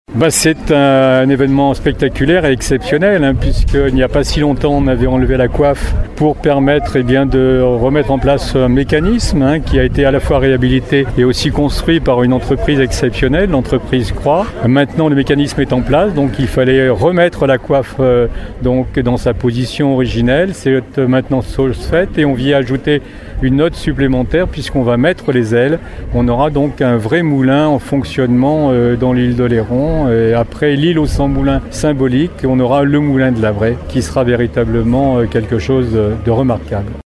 Une opération spectaculaire donc, suivie sur place par plus de 150 personnes et qui ravit aujourd’hui Michel Parent, président de la Communauté de communes de l’Ile d’Oléron en charge du chantier :